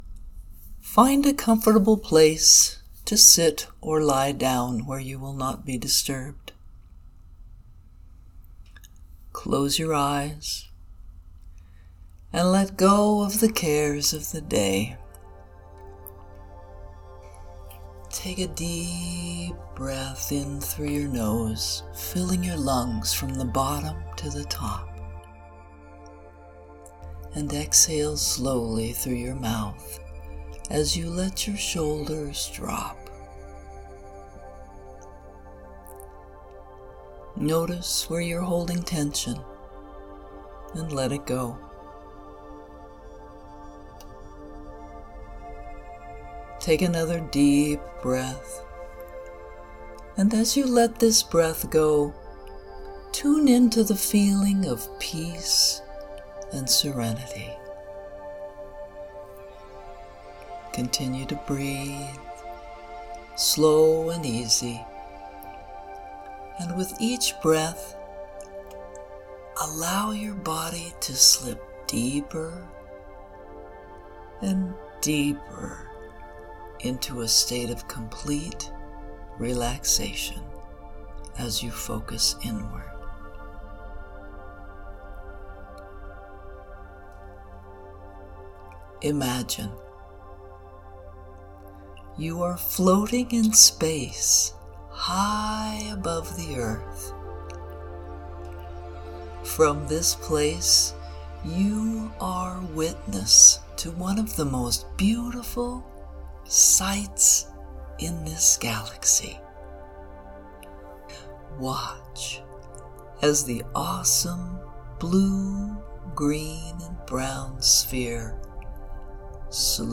March 2026 Meditation:   View the Earth from space and send love and peace to water everywhere – infusing your love and peaceful intent  for everyone and everything on the planet.    Music in this Meditation by Relaxing Time at Pixabay.